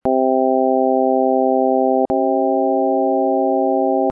third sound clip has two 2-second tones, the first harmonic,the second anharmonic. The frequencies in the first are 262, 392.5, 523, 664, 784 Hz, being exactly the 1st to 5th harmonics of C3 (131 Hz -- not itself present).
I hear a slight rise (sharpening) in pitch by about half a semitone (3%).
anharmonic-4.mp3